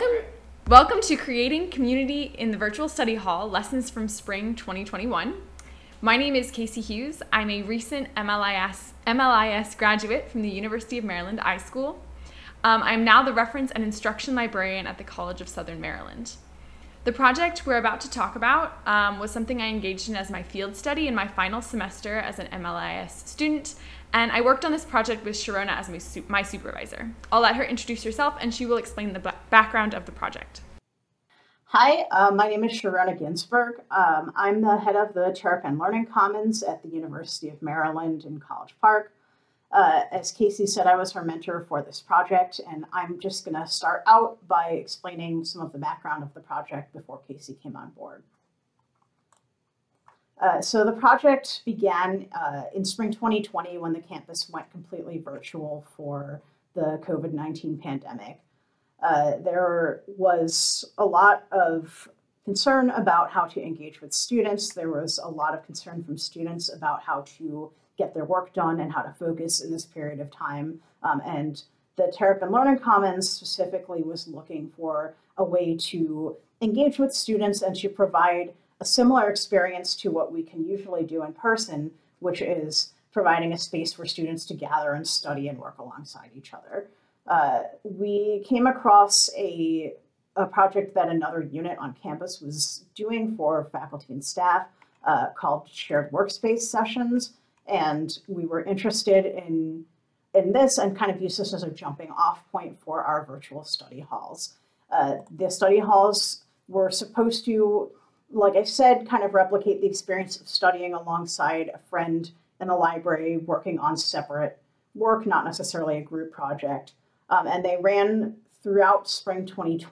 Presented virtually July 28, 2021 at the Towson Conference for Academic Libraries.